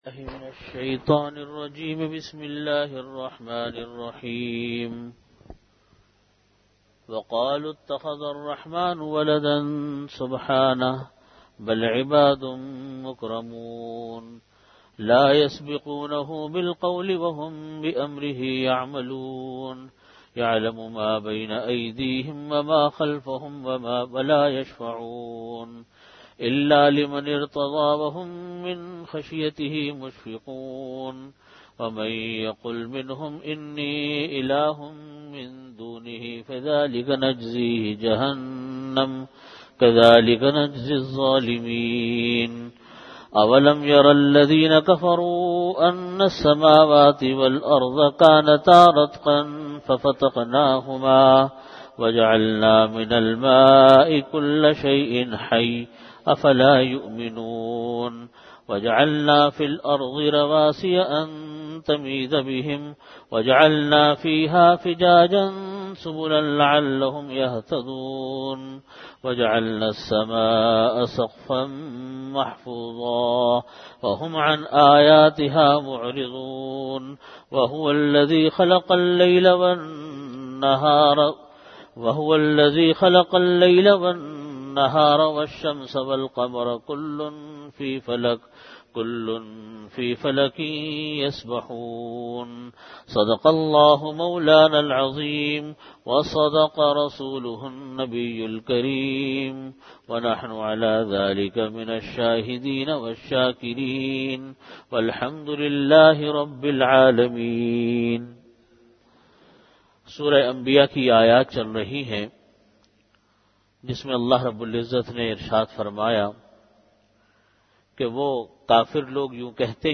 Audio Category: Bayanat
Time: After Asar Prayer Venue: Jamia Masjid Bait-ul-Mukkaram, Karachi